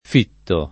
f&tto] (ant. fisso [